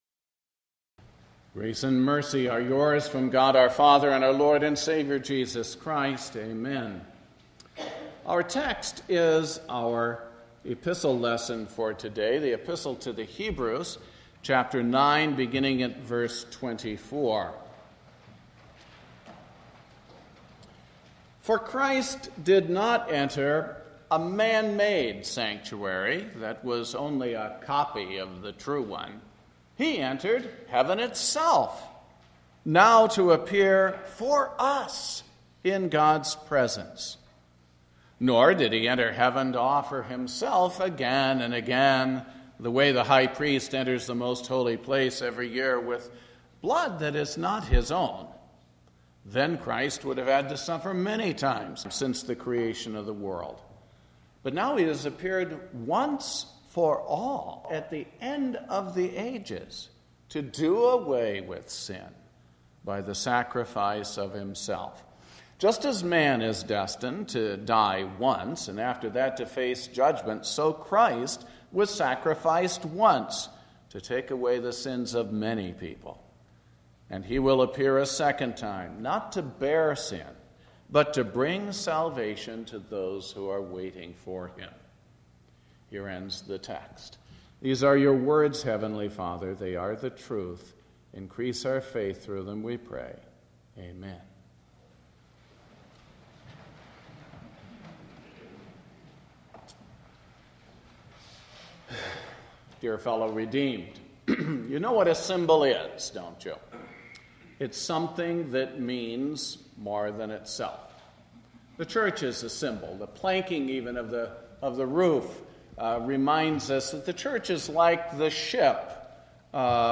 Christ Will Appear A Second Time – St. Timothy Evangelical Lutheran Church in Lombard Illinois